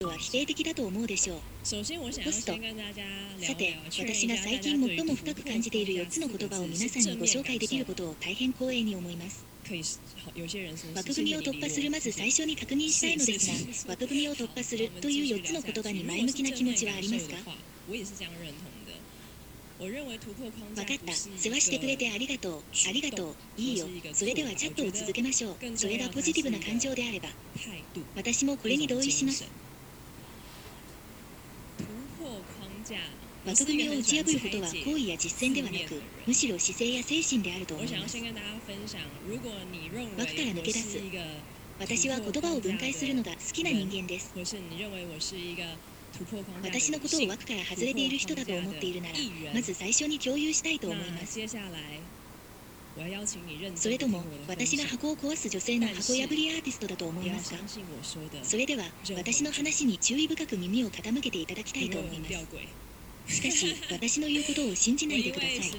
本モードの場合も、TEDの音声を翻訳させてみた。
※翻訳中のアプリ画面と、イヤホン音声の録音データを掲載している。